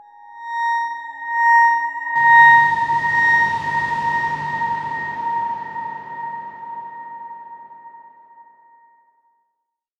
X_Darkswarm-A#5-f.wav